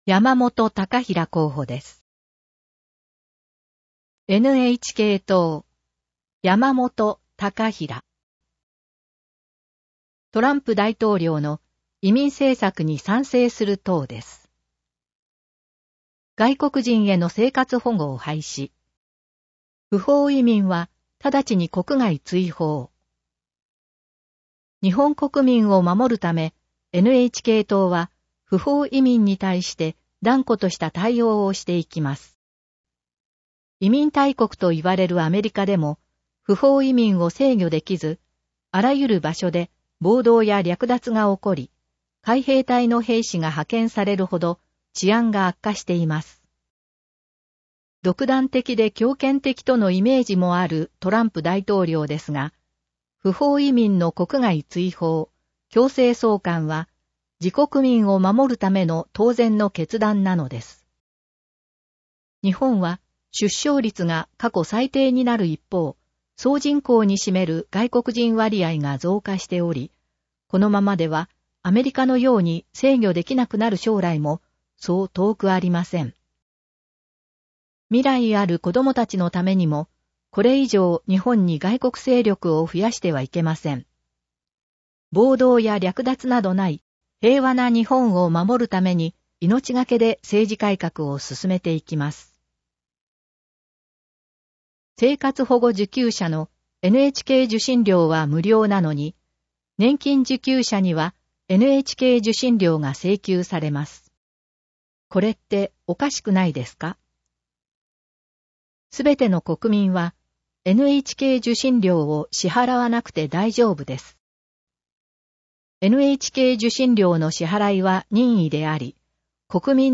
音声読み上げ対応データ（MP3：800KB）